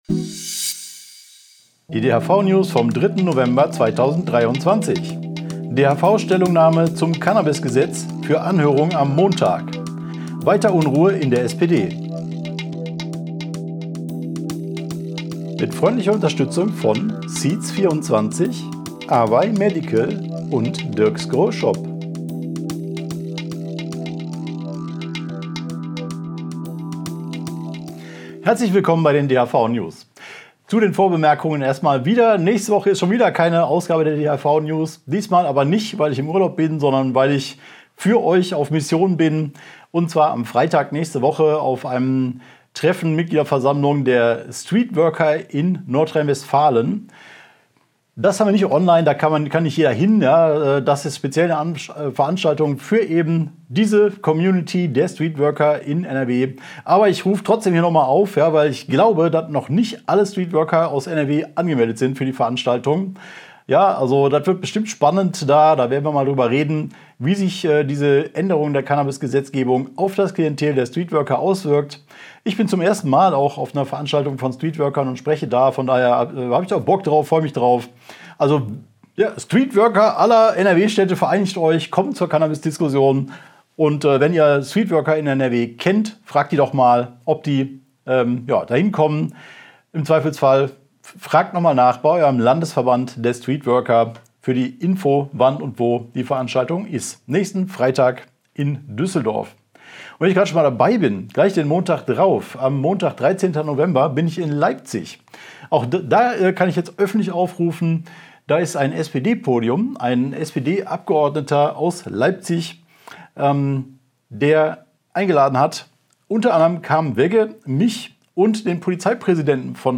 Video-News